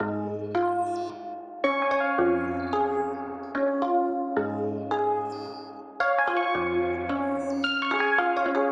标签： 110 bpm Trap Loops Bells Loops 1.47 MB wav Key : D
声道立体声